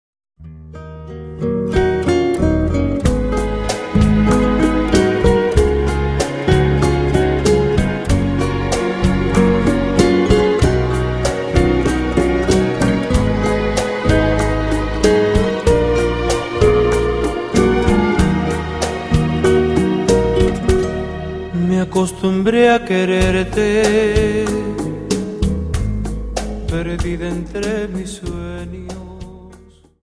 Dance: Rumba
Bolero Version